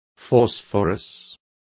Complete with pronunciation of the translation of phosphorus.